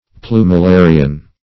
Plumularian \Plu`mu*la"ri*an\, n.